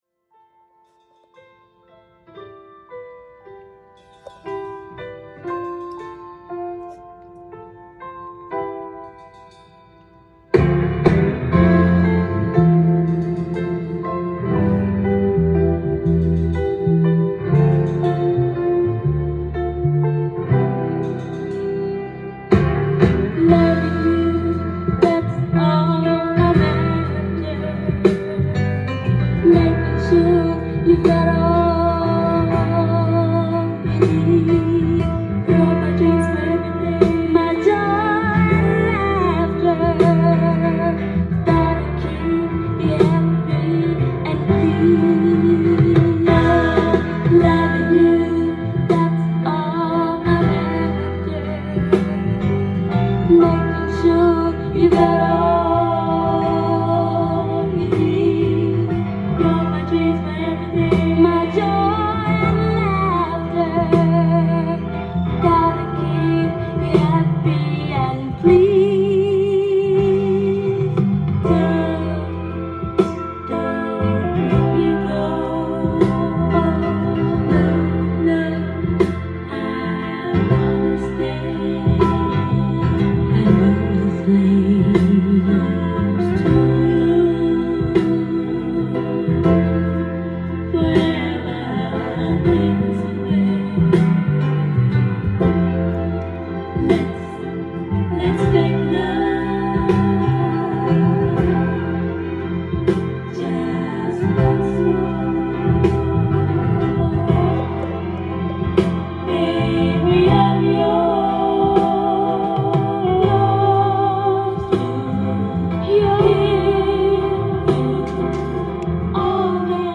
店頭で録音した音源の為、多少の外部音や音質の悪さはございますが、サンプルとしてご視聴ください。
ピアノの旋律からスムースなヴォーカルが入るスロウ
オーケストラアレンジが壮大なグループ3作目！！